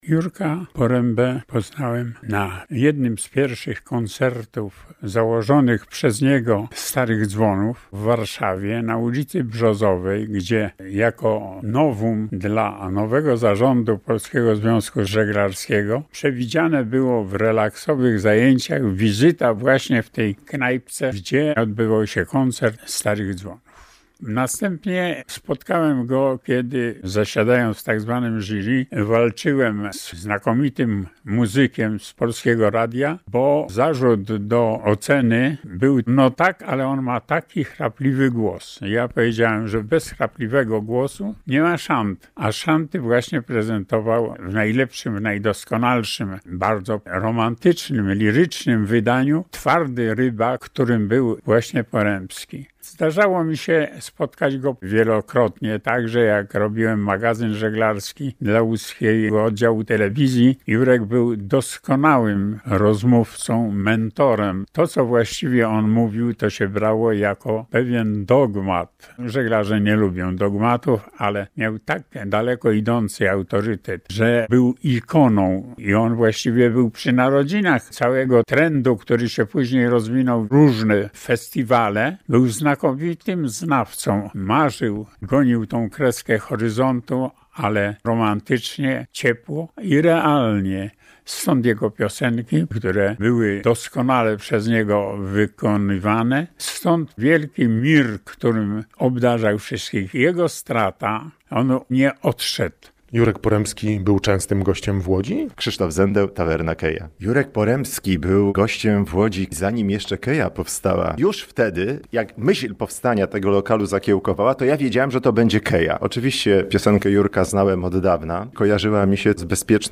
Łódzcy żeglarze i miłośnicy szant wspominają zmarłego Jerzego Porębskiego